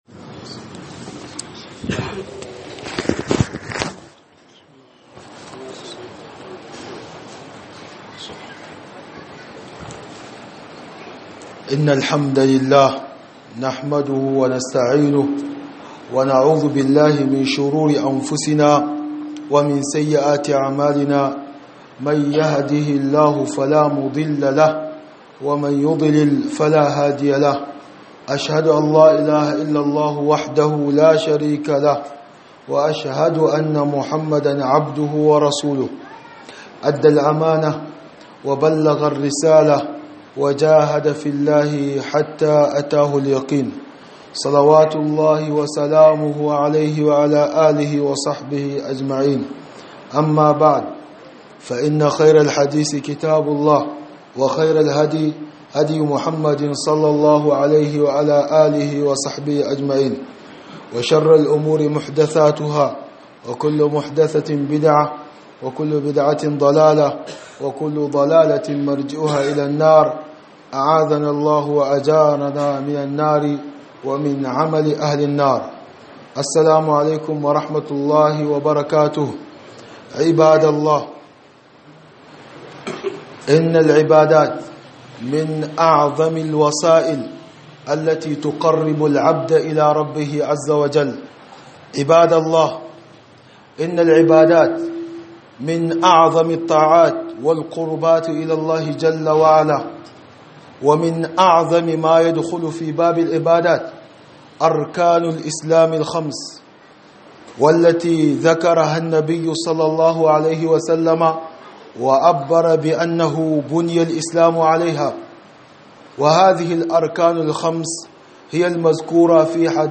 خطبة بعنوان الحج فريضة إسلامية